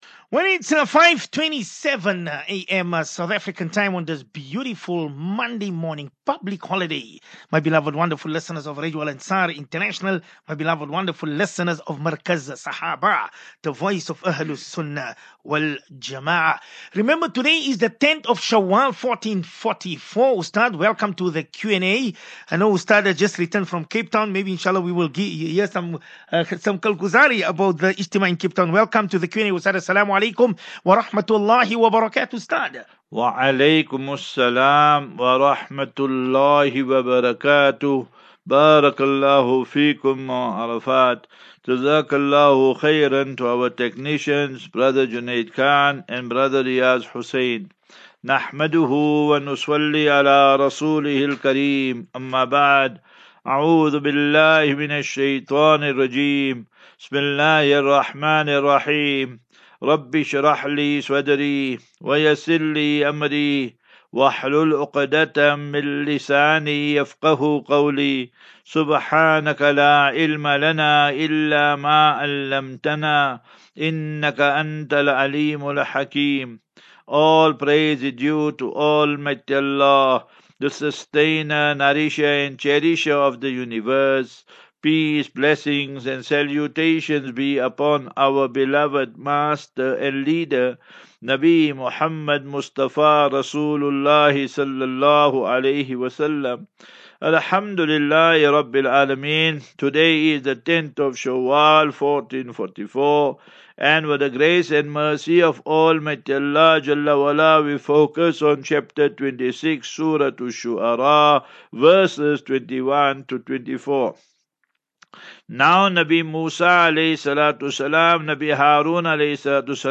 Daily Naseeha.
As Safinatu Ilal Jannah Naseeha and Q and A 1 May 01 May 23 Assafinatu